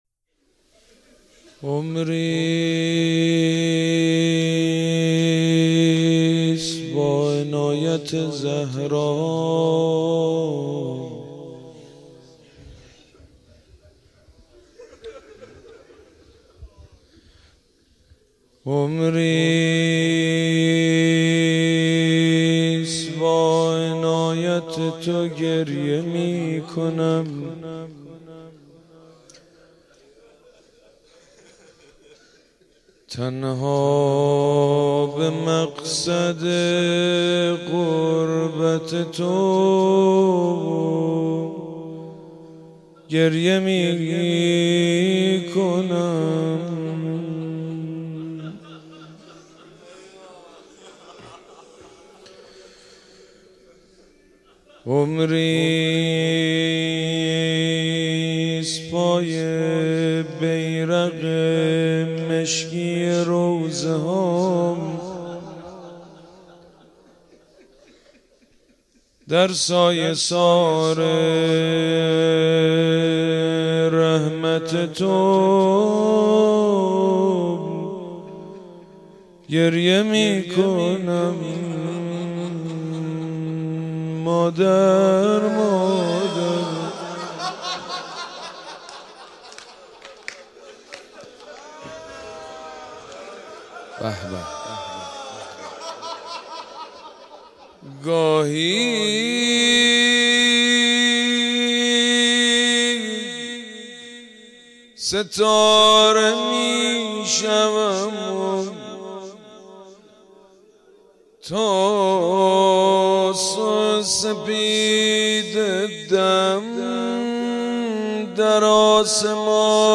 مداحی جدید حاج سید مجید بنی فاطمه شب اول فاطمیه 1397 مسجد حضرت امیر تهران